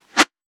weapon_bullet_flyby_03.wav